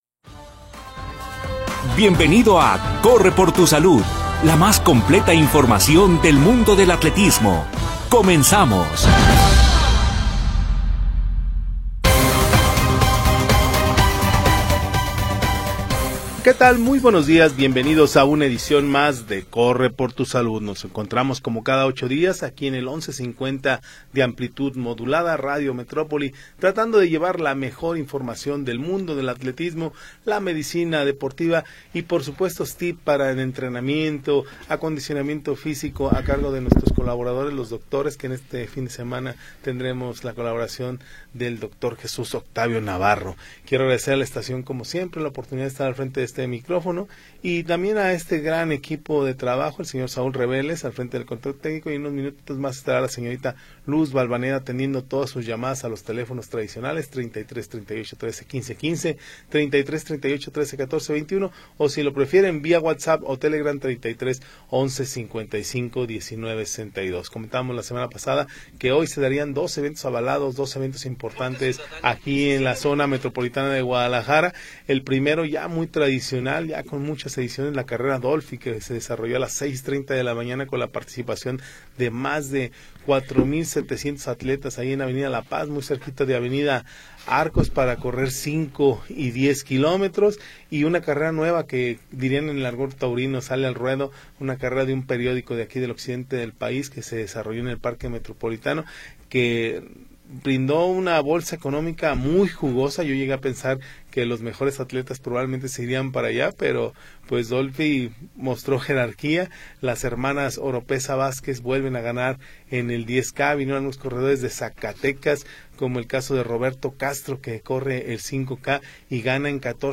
Atletismo, nutrición, ejercicio sin edad. Bajo la conducción del equipo de deportes Notisistema.